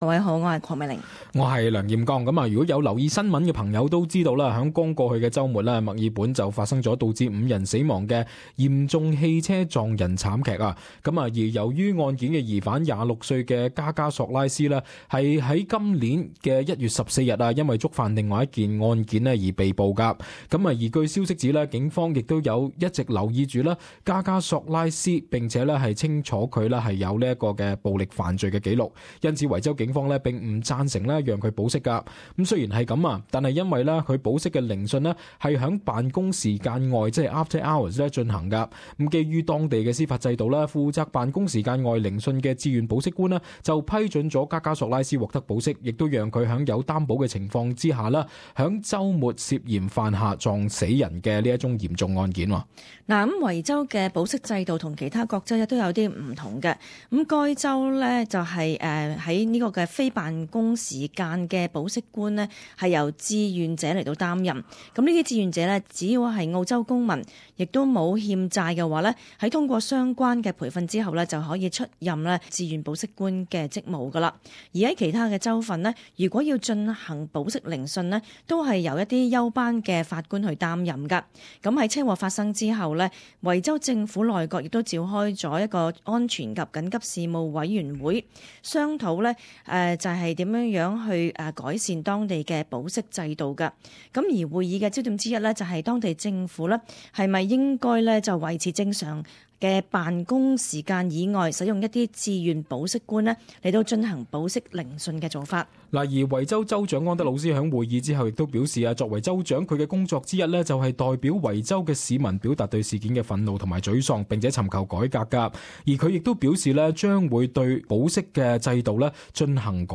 【时事报导】维州将推保释法改革回应车撞人惨剧